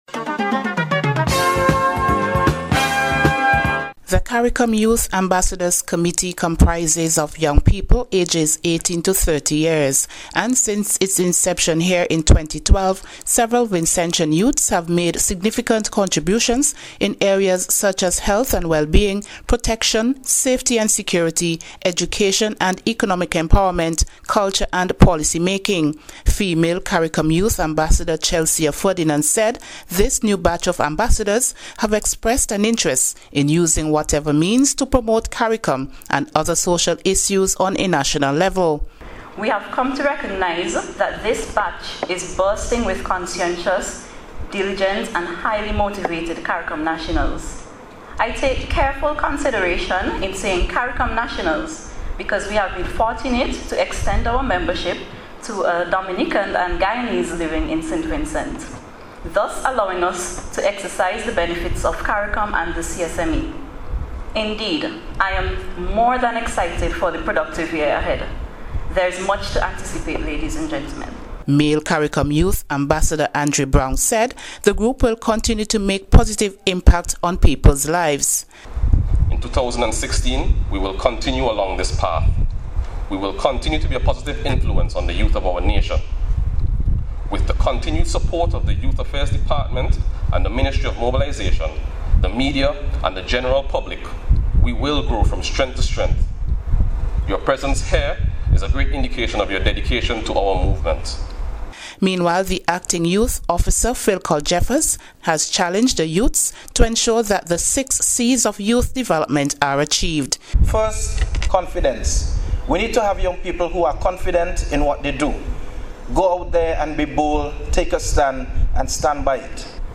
CARICOM Youth Ambassadors Initiation – Special Report